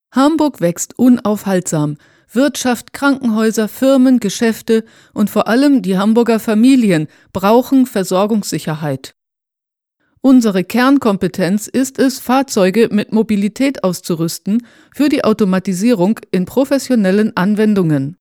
Almanca Seslendirme
Kadın Ses